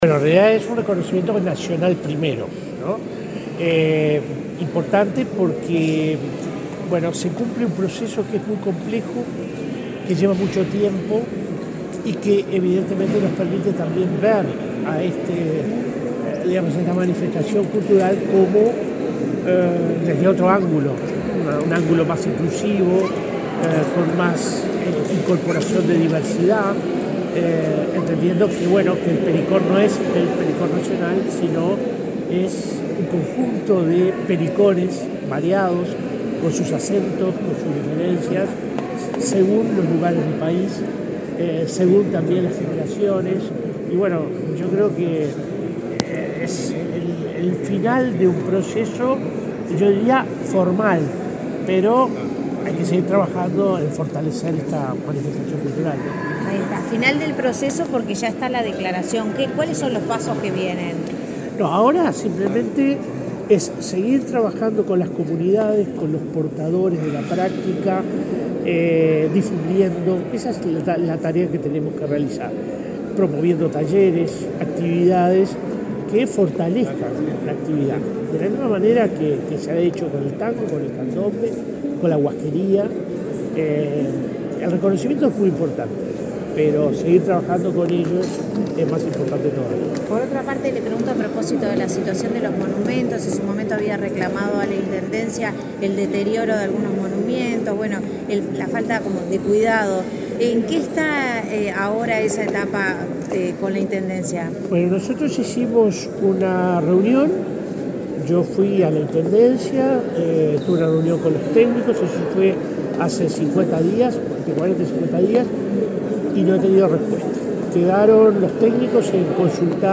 Declaraciones del director de la Comisión del Patrimonio 15/06/2023 Compartir Facebook X Copiar enlace WhatsApp LinkedIn El director de la Comisión del Patrimonio Cultural de la Nación, William Rey, dialogó con la prensa luego de participar en el acto de declaratoria del pericón nacional como Patrimonio Cultural Inmaterial del Uruguay.